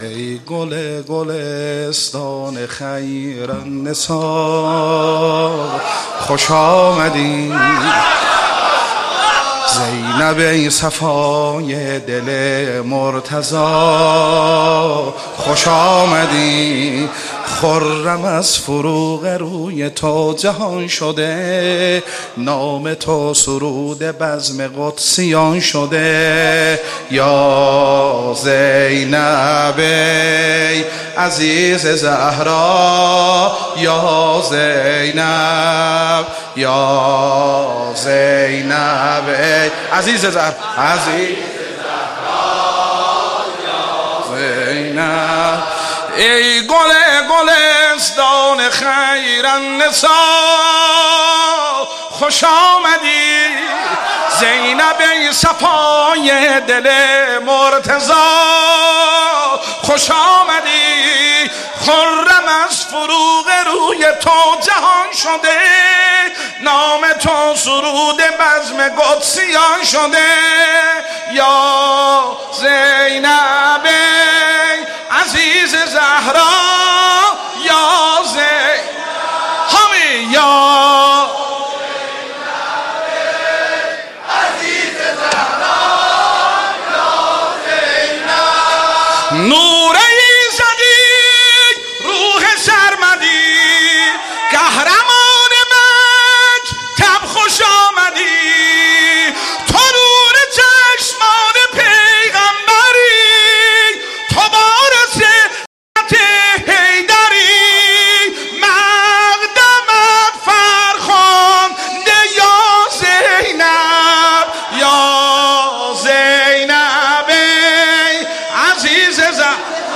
مولودی ترکی میلاد حضرت زینب کبری سلام الله علیها